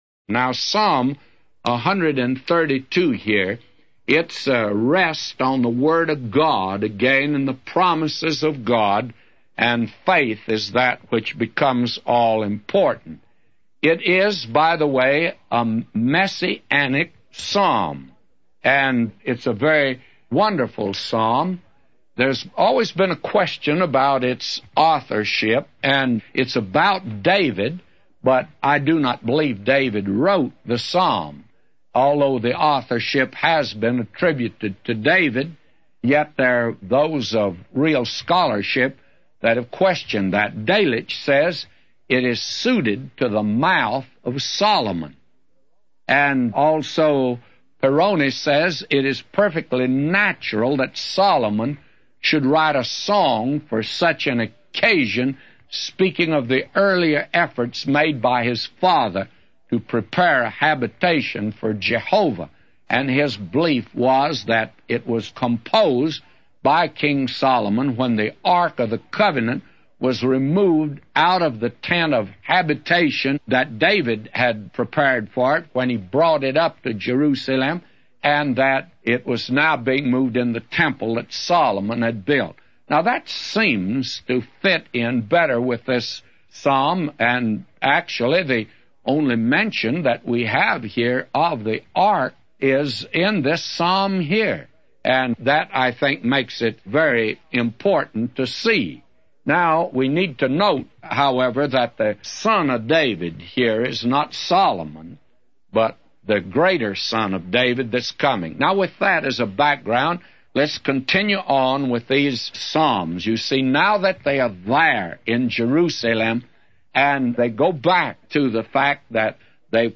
A Commentary By J Vernon MCgee For Psalms 132:1-999